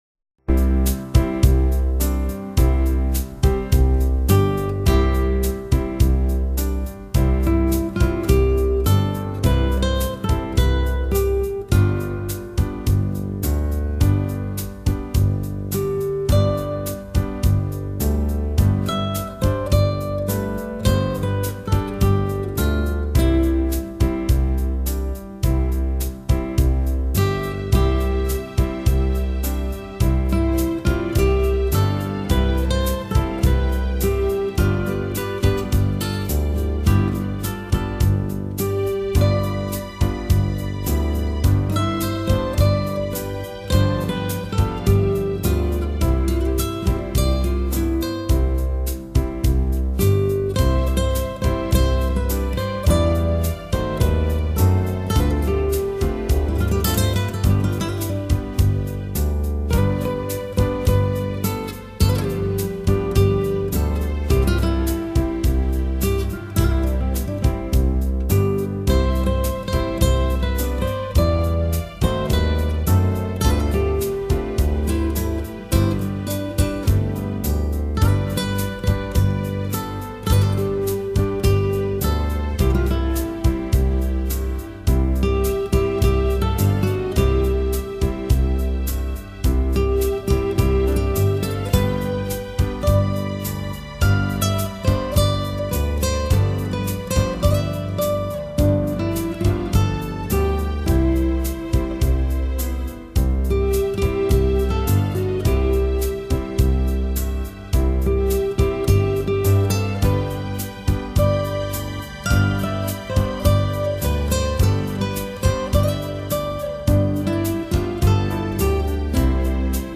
Genre: Instrumental Guitar